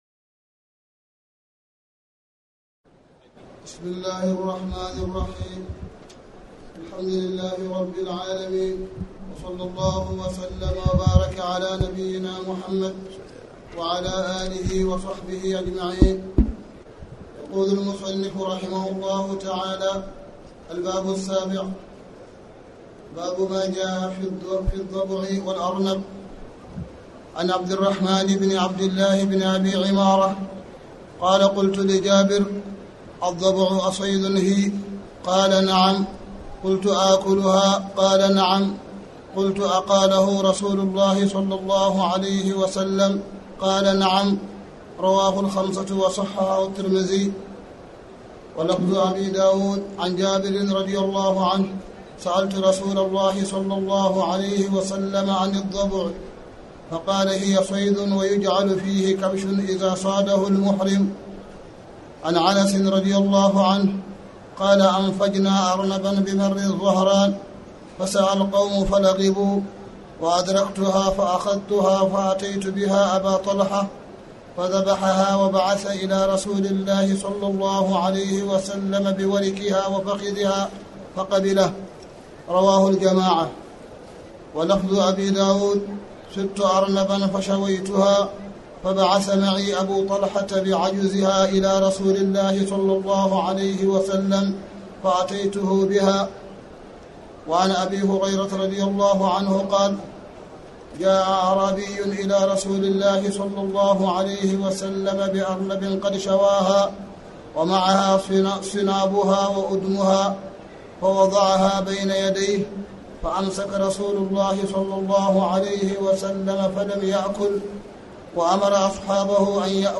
تاريخ النشر ٢٨ ذو القعدة ١٤٣٨ هـ المكان: المسجد الحرام الشيخ: معالي الشيخ أ.د. صالح بن عبدالله بن حميد معالي الشيخ أ.د. صالح بن عبدالله بن حميد باب ما جاء في الضبع والأرنب